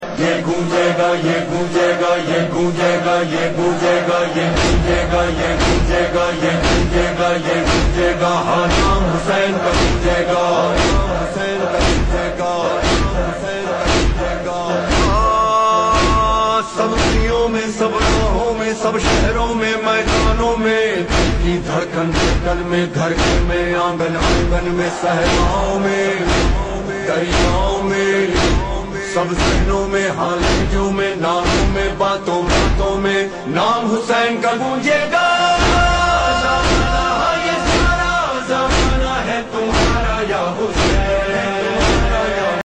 قدم گاہ مولا علی ع حیدرآباد سندھ کے مناظر ہیں
ندیم سرور نے نوحہ خوانی کی